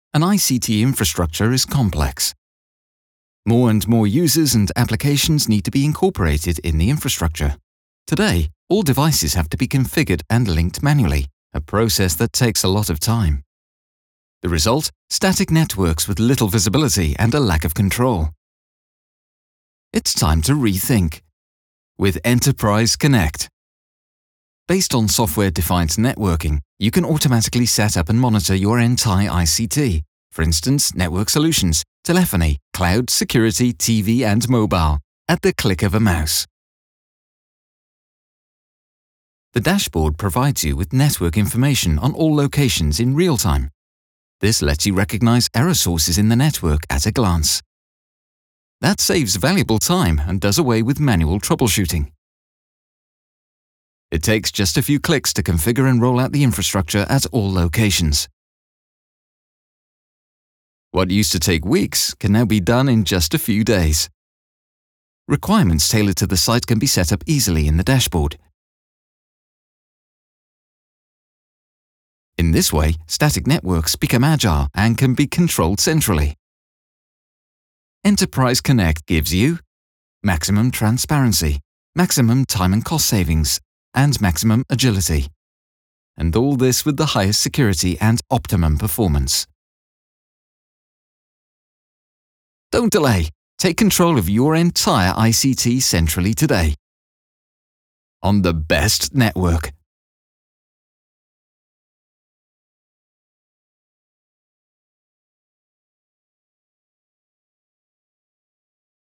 OFF-Voice Englisch (UK)